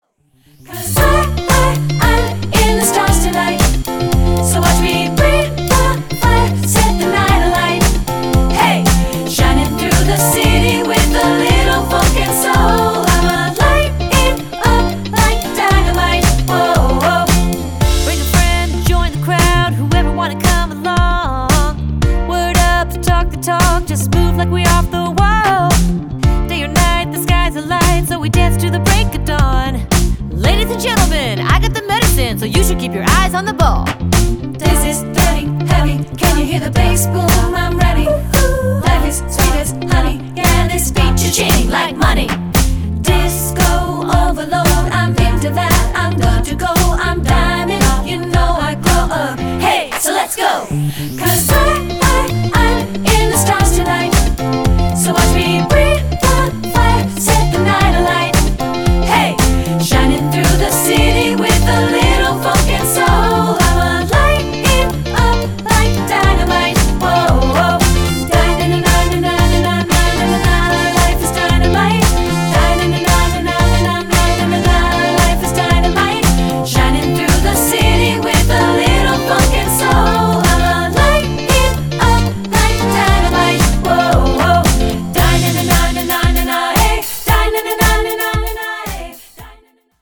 Choral Recent Pop Hits Women's Chorus
SSA